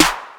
CDK - CMP Snare.wav